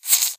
mob / silverfish / say4.ogg